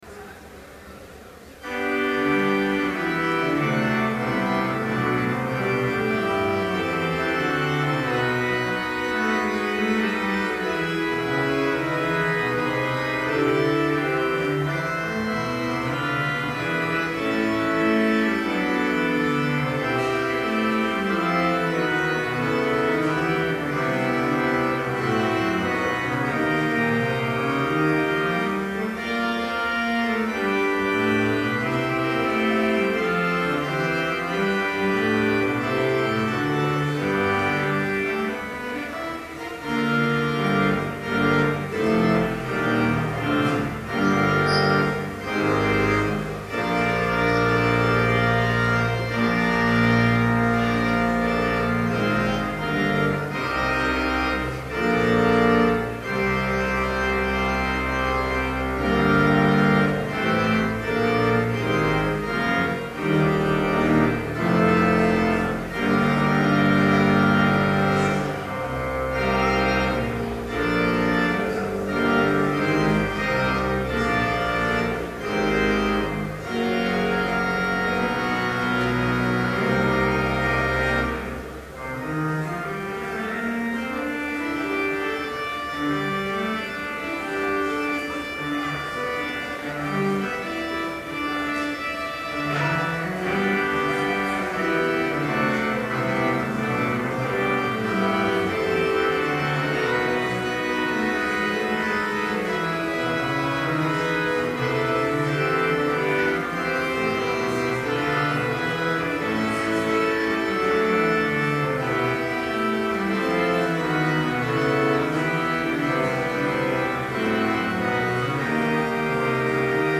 Complete service audio for Chapel - January 26, 2012